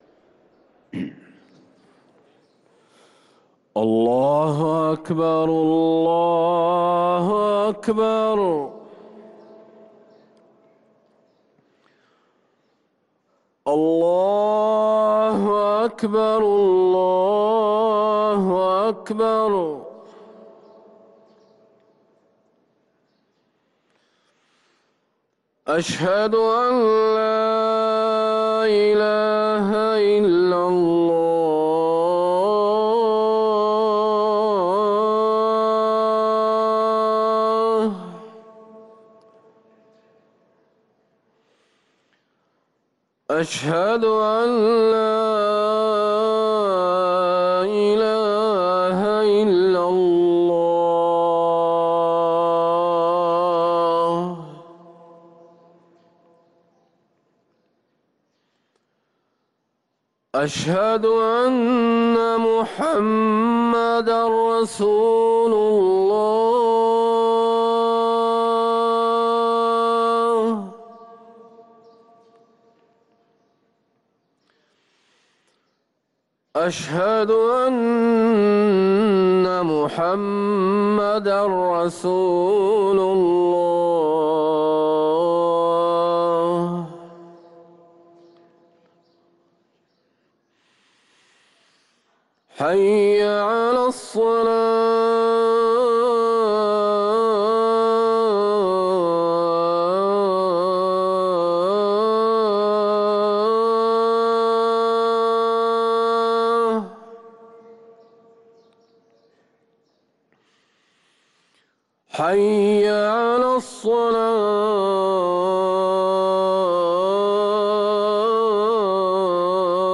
أذان الجمعة الأول
ركن الأذان